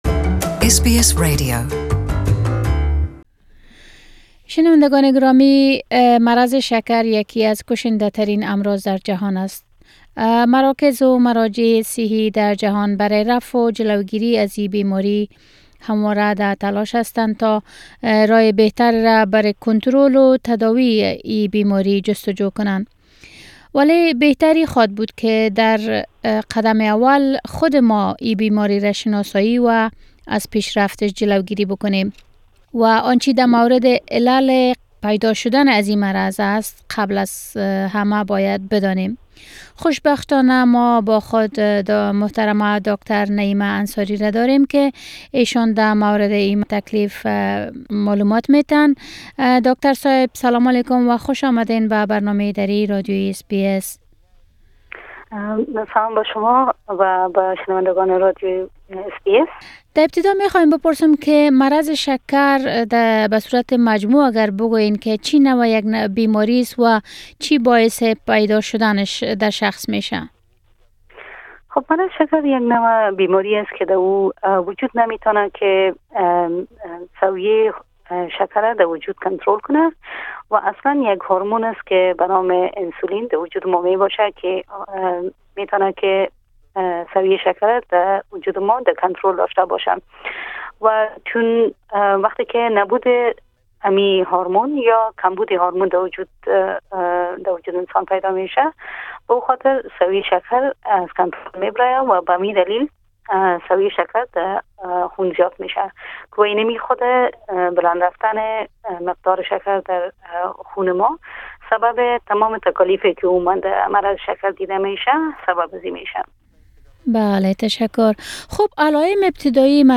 بيمارى شكر و خطرات ناشى از آن. به مصاحبه